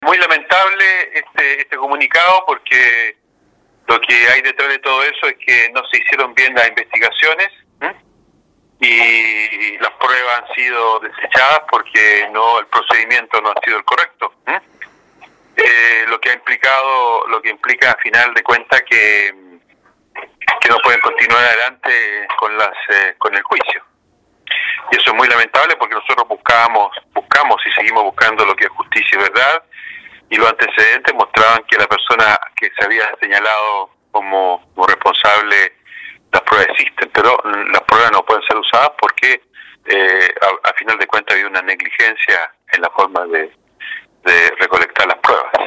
El Obispo de la Diócesis San Carlos de Ancud, Juan María Agurto, reconoció el impacto que genera esta decisión, en especial para la comunidad ancuditana, calificándola de lamentable.